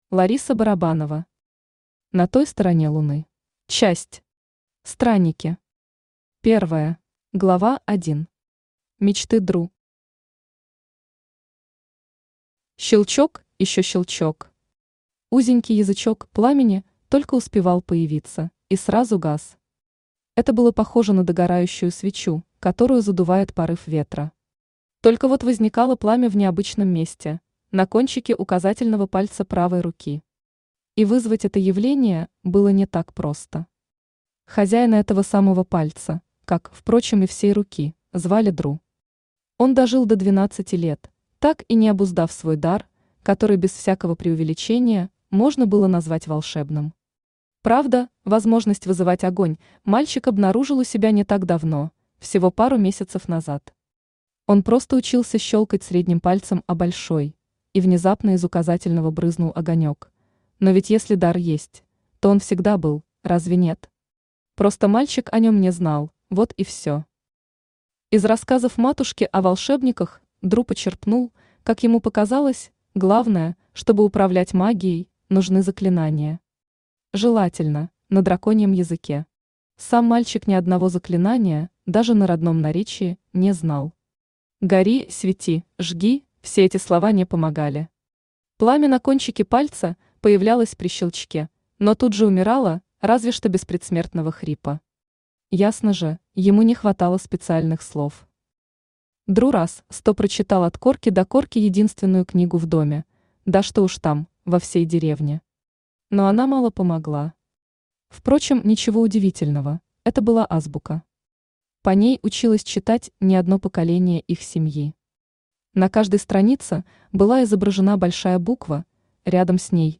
Аудиокнига На той стороне Луны | Библиотека аудиокниг
Aудиокнига На той стороне Луны Автор Лариса Барабанова Читает аудиокнигу Авточтец ЛитРес.